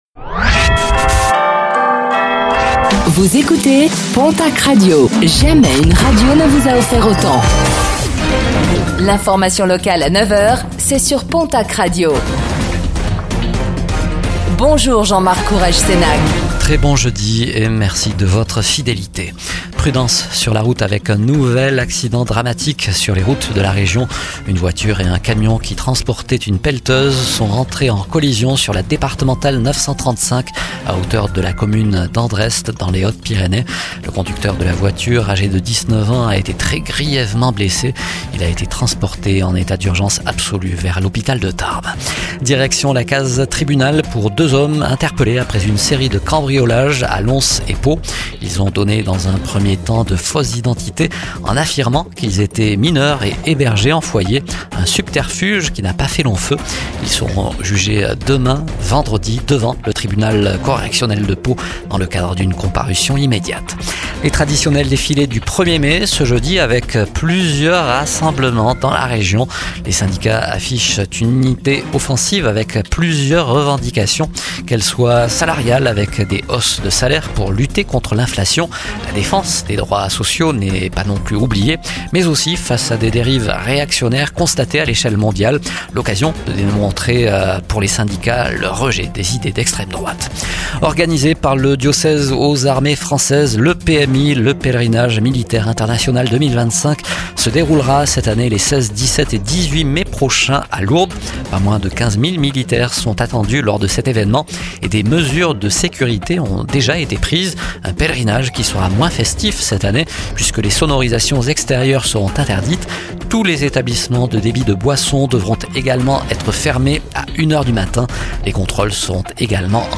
Réécoutez le flash d'information locale de ce jeudi 1er mai 2025